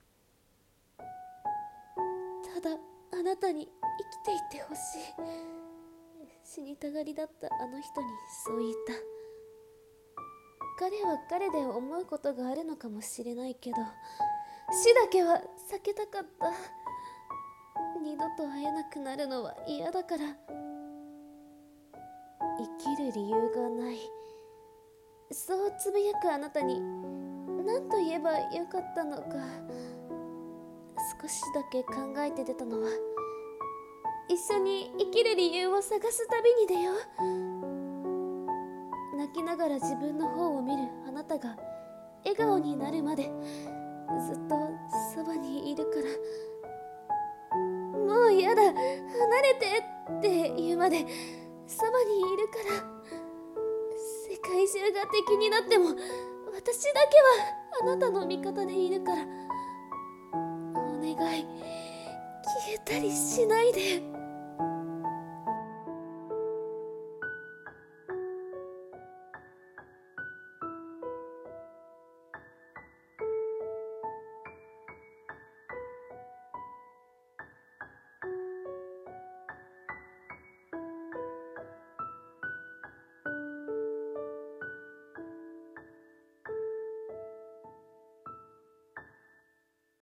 〖声劇〗魔法使いに